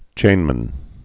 (chānmən)